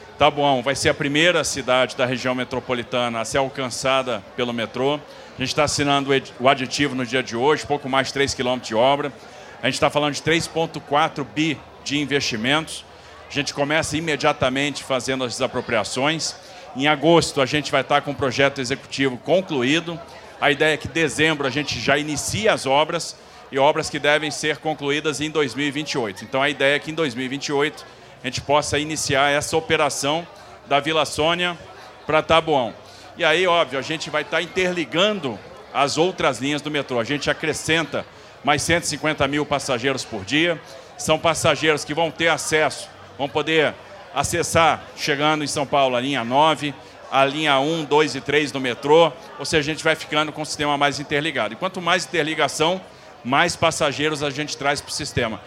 ENTREVISTA: Tarcísio promete início das obras da linha 4 em dezembro, operação até Taboão da Serra em 2028 e diz que pedido de reequilíbrio pela ViaQuatro é “normal”
As promessas foram feitas em cerimônia de assinatura do termo aditivo para elaboração dos estudos de viabilidade e dos projetos executivos da extensão da Linha 4-Amarela de metrô, em Taboão da Serra.